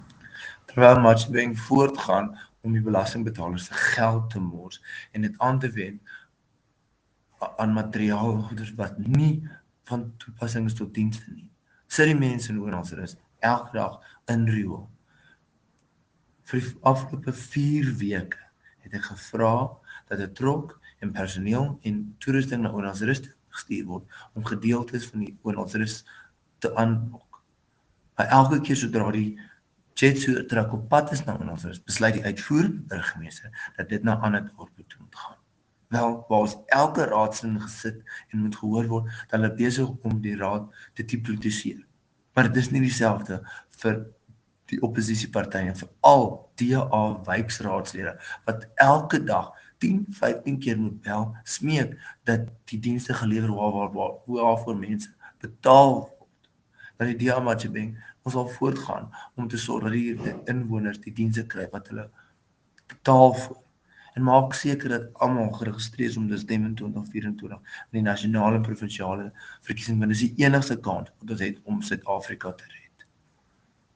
Afrikaans soundbites by Cllr Igor Scheurkogel and